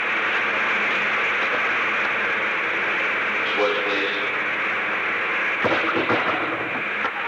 Participants: Nixon, Richard M. (President) ; White House operator
The Oval Office taping system captured this recording, which is known as Conversation 660-011 of the White House Tapes.